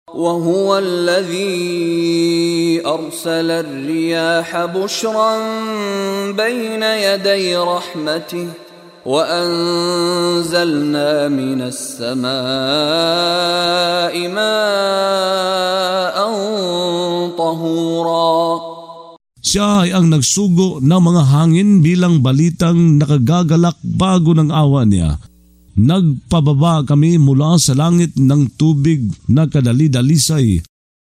Pagbabasa ng audio sa Filipino (Tagalog) ng mga kahulugan ng Surah Al-Furqan ( Ang Pamantayan ) na hinati sa mga taludtod, na sinasabayan ng pagbigkas ng reciter na si Mishari bin Rashid Al-Afasy.